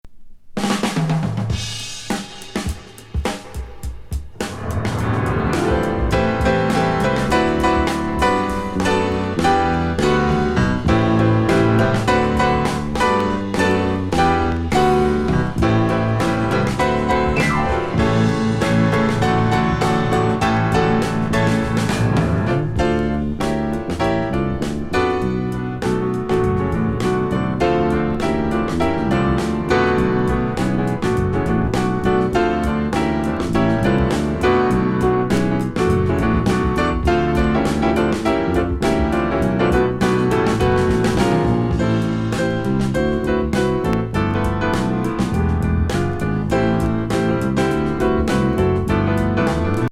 ピアニスト